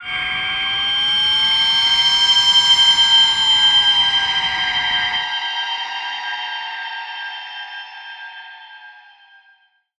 G_Crystal-G8-mf.wav